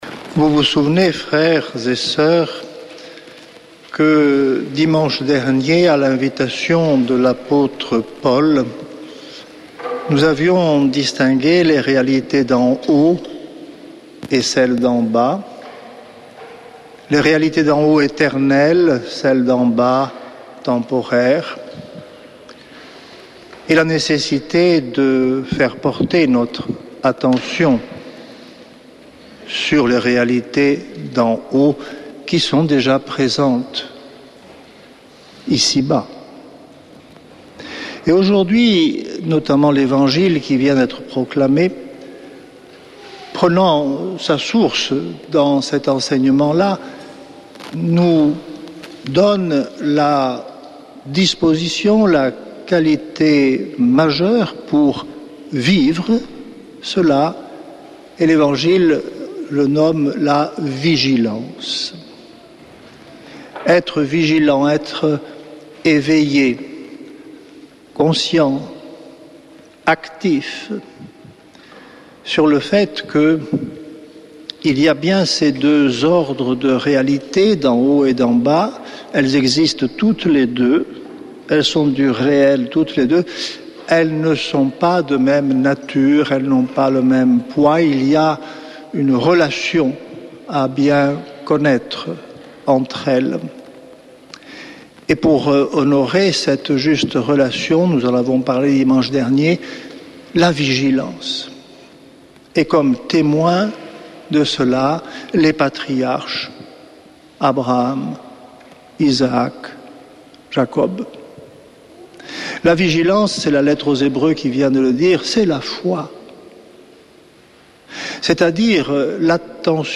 Homélie depuis le couvent des Dominicains de Toulouse du 10 août
Frères de la communauté